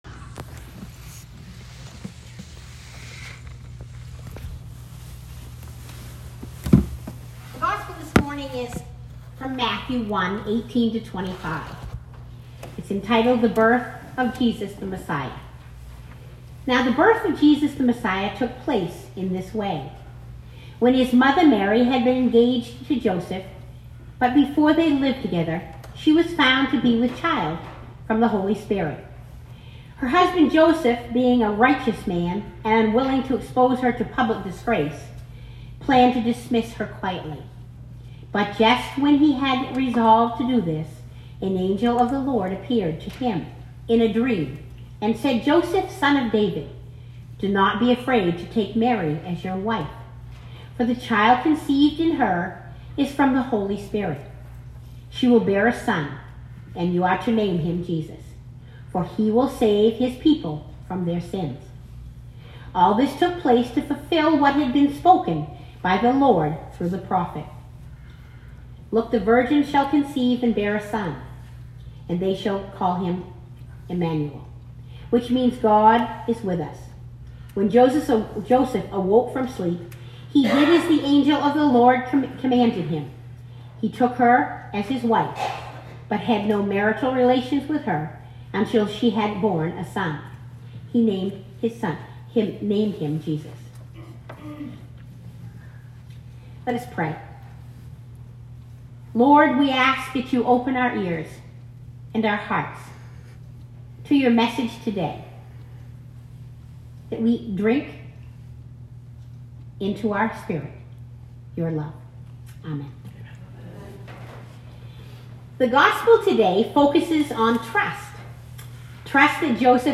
Sermon 2019-12-22